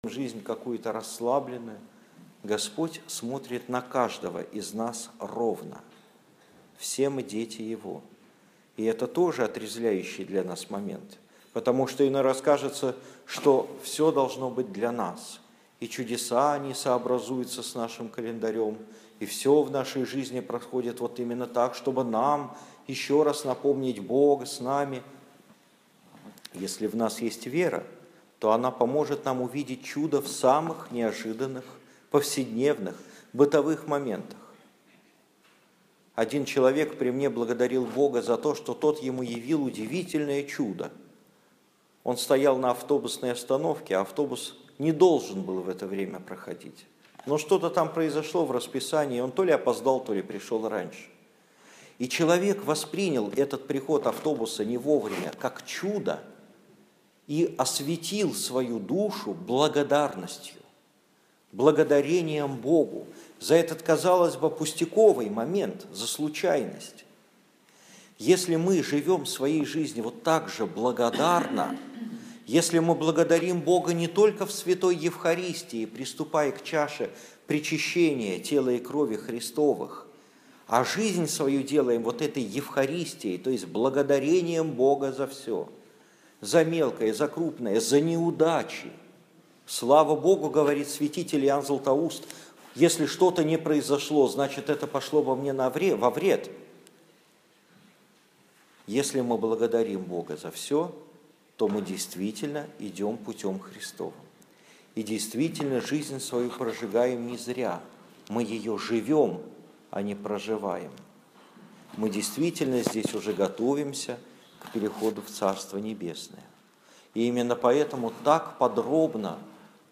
Домой / Проповеди / Аудио-проповеди / 23 августа 2015 года. Проповедь на воскресной литургии